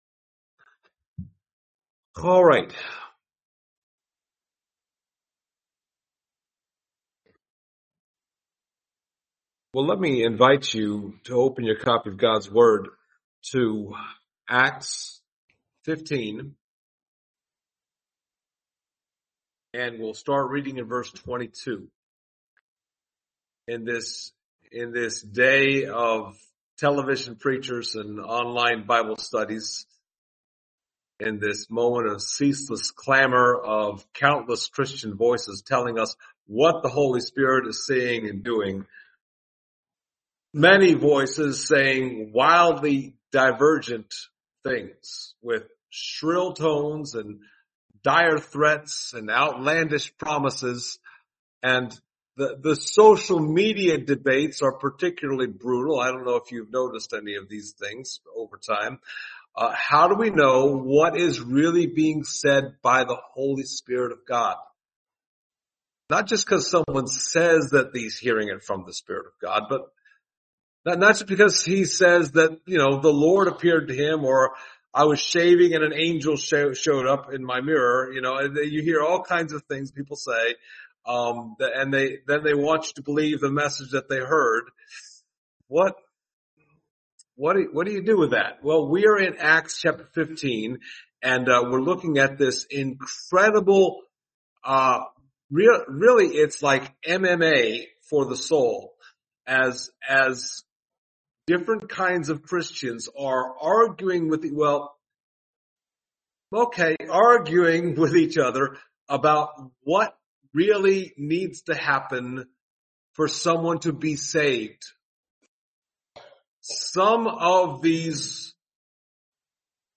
Passage: Acts 15:22-35 Service Type: Sunday Morning